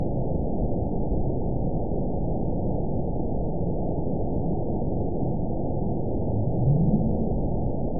event 922798 date 04/06/25 time 22:27:17 GMT (2 months, 1 week ago) score 8.68 location TSS-AB02 detected by nrw target species NRW annotations +NRW Spectrogram: Frequency (kHz) vs. Time (s) audio not available .wav